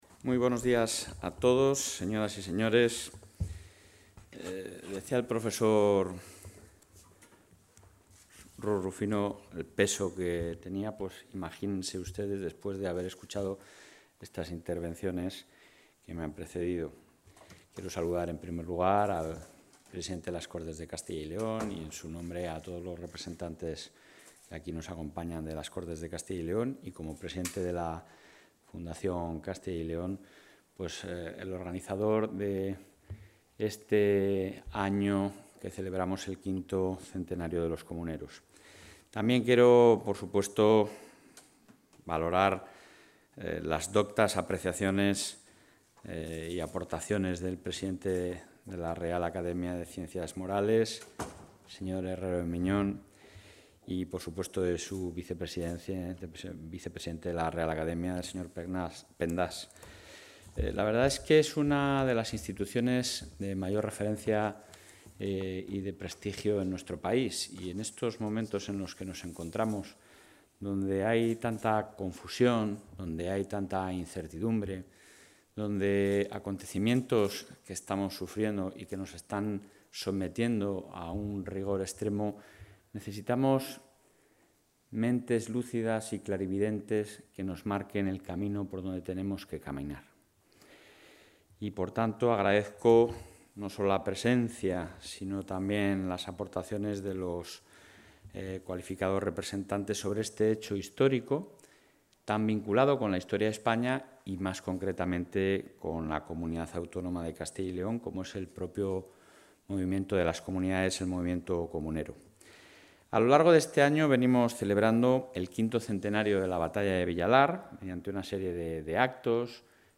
El presidente de la Junta de Castilla y León, Alfonso Fernández Mañueco, ha clausurado hoy el acto académico ‘El...
Intervención del presidente de la Junta.